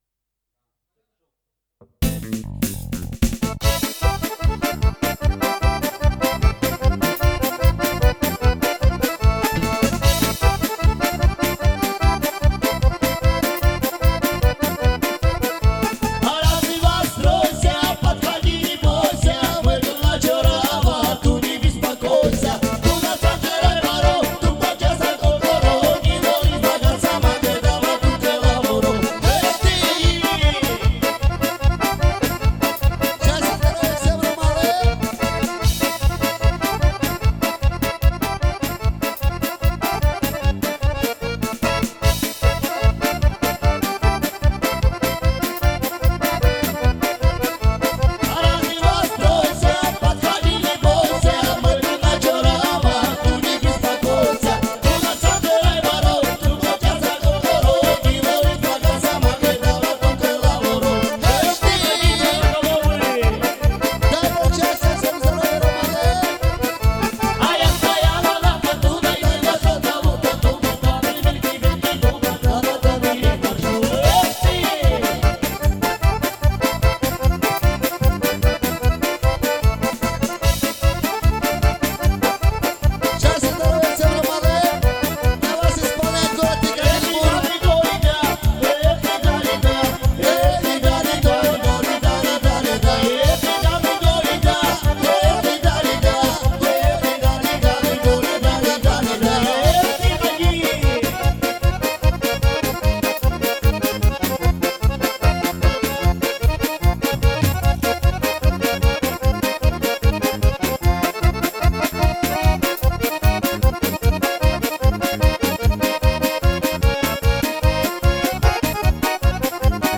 Клавиши